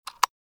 دانلود صدای موس 31 از ساعد نیوز با لینک مستقیم و کیفیت بالا
جلوه های صوتی
برچسب: دانلود آهنگ های افکت صوتی اشیاء دانلود آلبوم صدای کلیک موس از افکت صوتی اشیاء